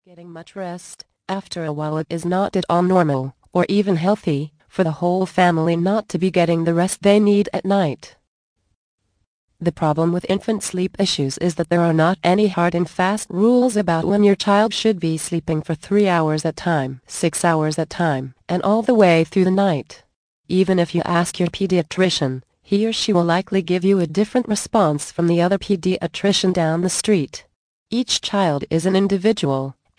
The Magic of Sleep audio book Vol. 7 of 14, 69 min.